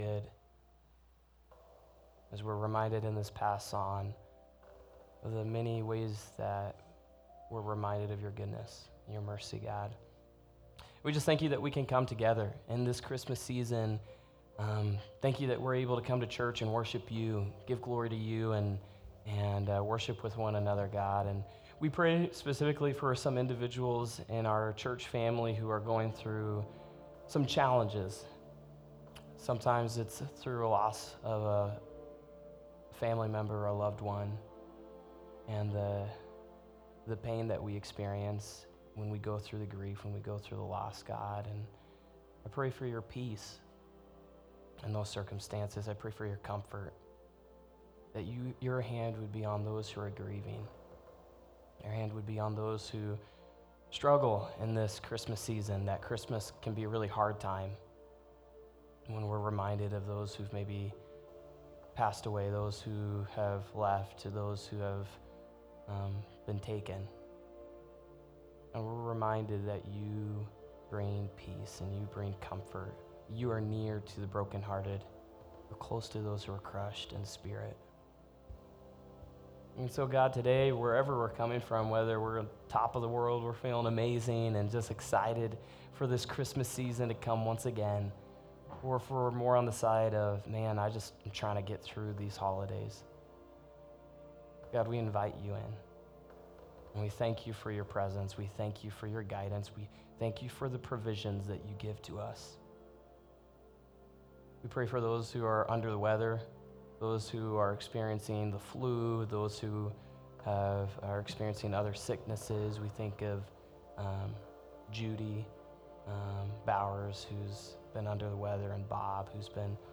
December 22nd, 2024 - Sunday Service - Wasilla Lake Church